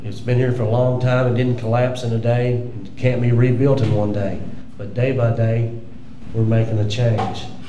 addressed the crowd